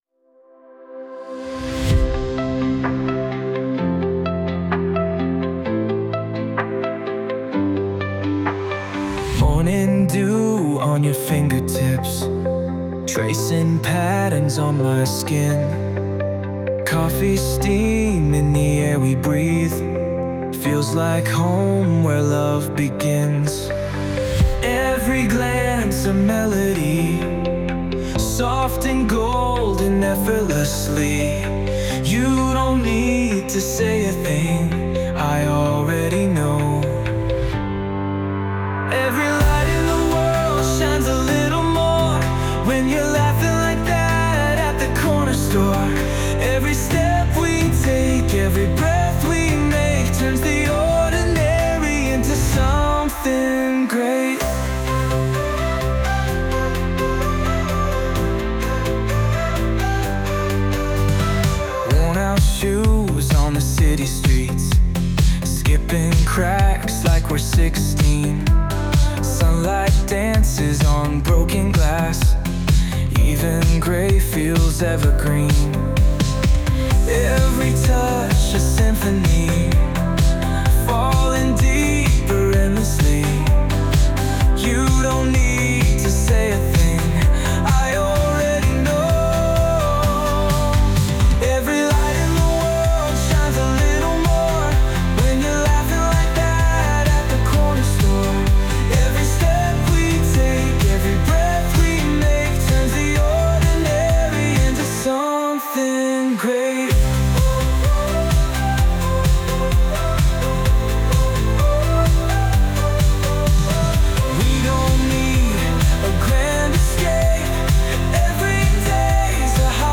洋楽男性ボーカル著作権フリーBGM ボーカル
男性ボーカル（洋楽・英語）曲です。